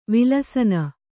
♪ vilasana